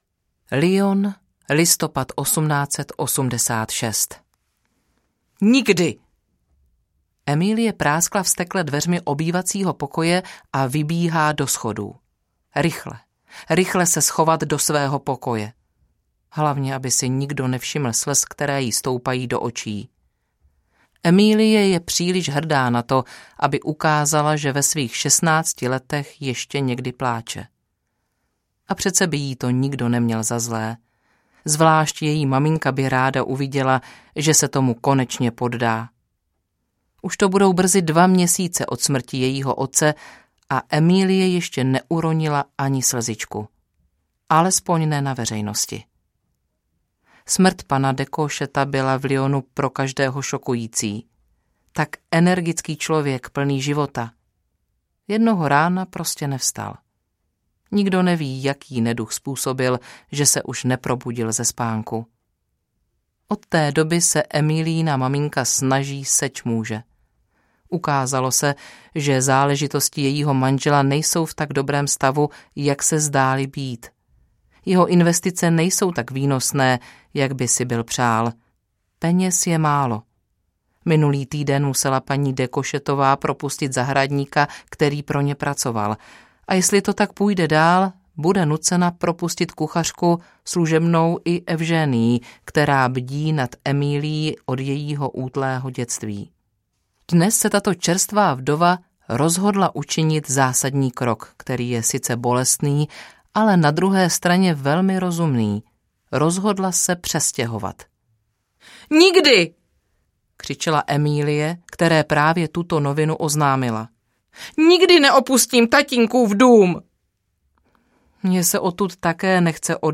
Podepsána Charlotte audiokniha
Ukázka z knihy
podepsana-charlotte-audiokniha